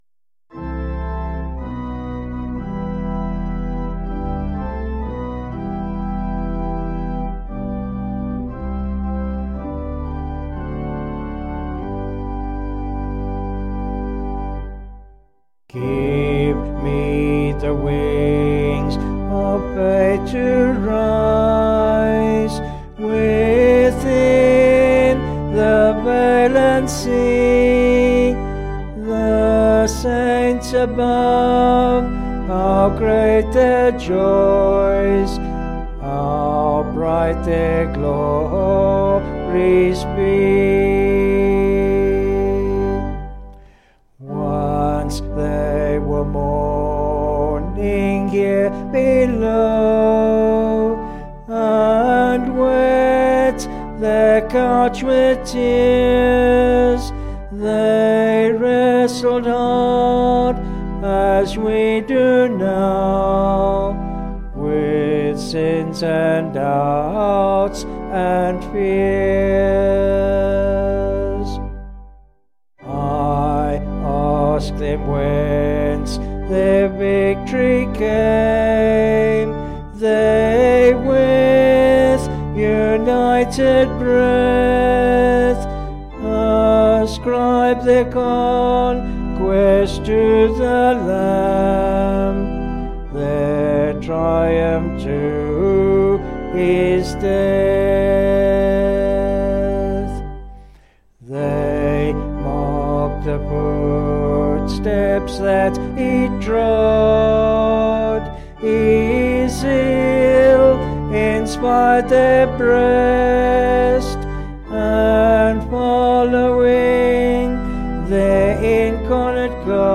Vocals and Organ   262.5kb Sung Lyrics